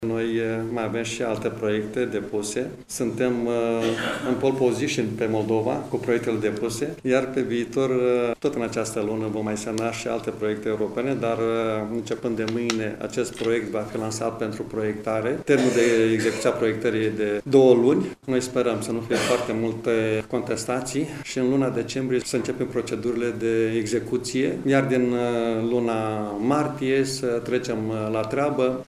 La rândul său, preşedintele Consiliului Judeţean Iaşi, Maricel Popa şi-a exprimat speranţa că în martie,  anul viitor,  se va putea trece la implementarea proiectului de infrastructură: